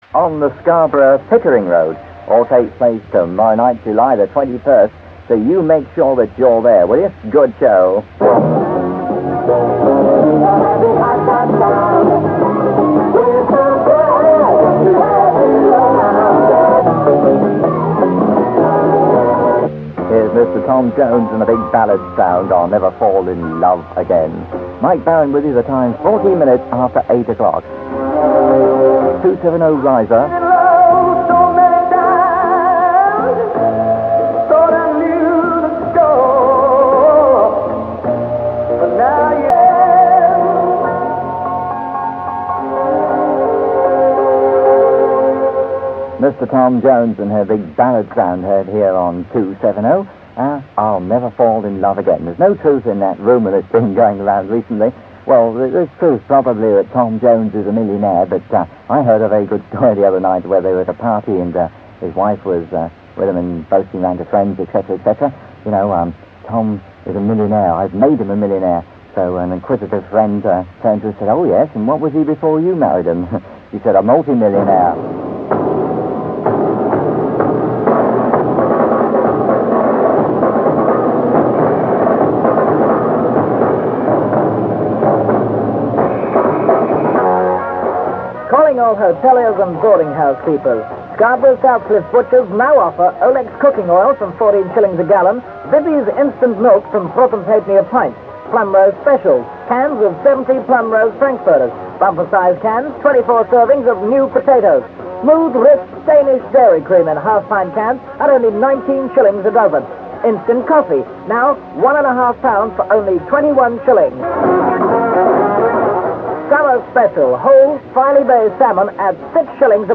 However, during the planning of the station it was decided to switch to a Top 40 format, with most programmes presented live from the ship. The station’s intention to aim for a local audience was reflected in the huge amount of commercials from small local businesses - many of which can be heard in these programme extracts:-